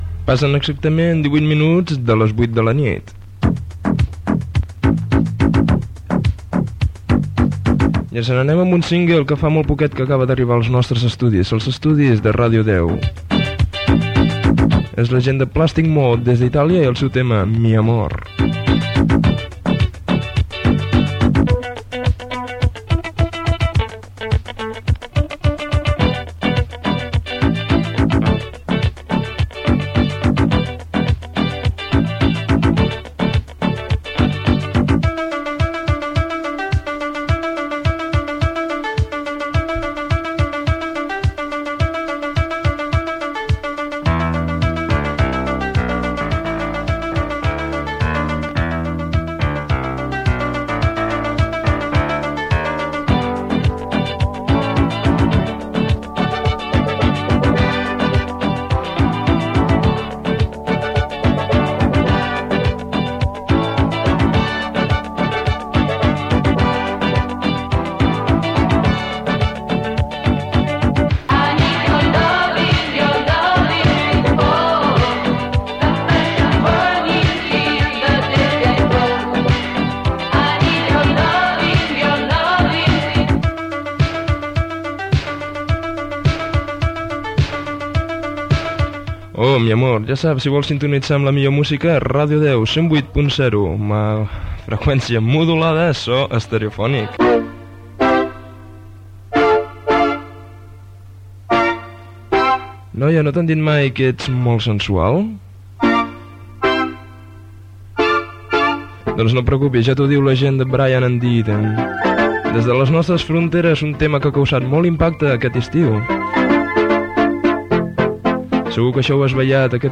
Identificació i temes musicals